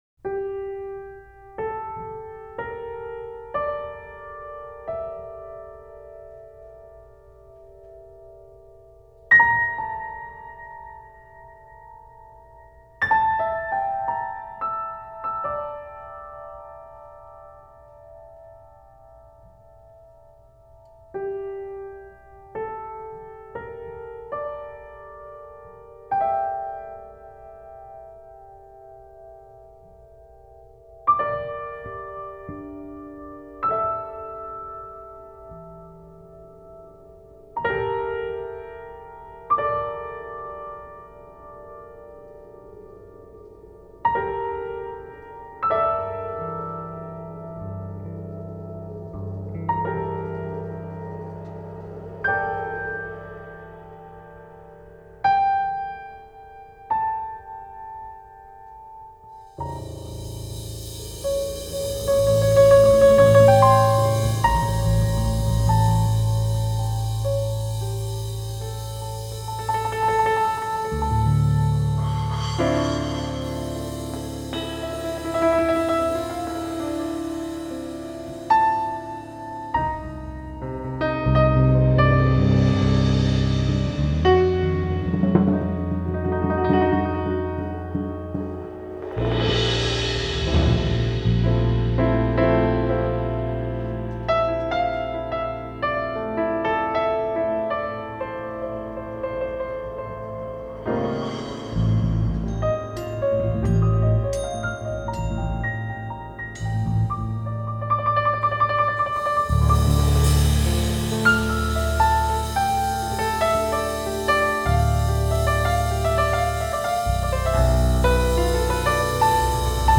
piano
fretless electric bass
drums